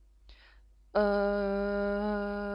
SOUNDS OF THE ROMANIAN LANGUAGE
Vowels